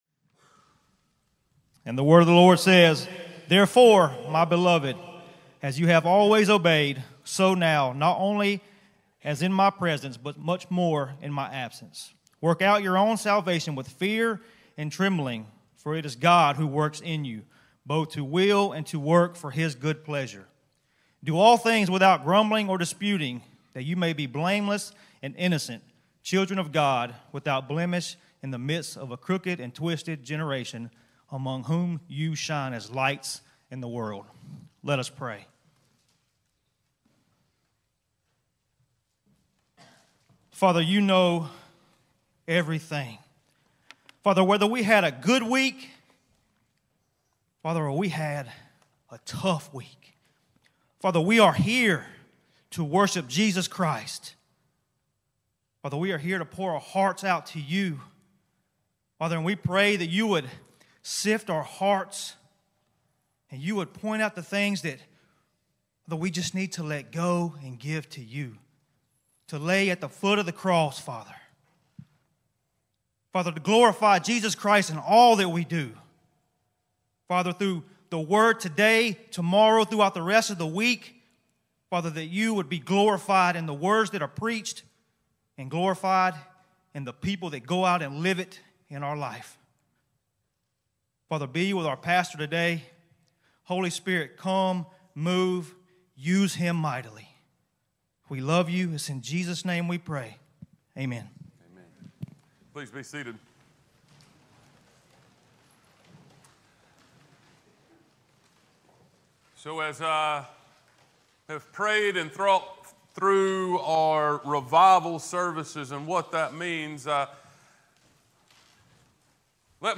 The King's Power Passage: Philippians 2:12-15 Service Type: Sunday Worship « The King’s Reward Reacting to King Jesus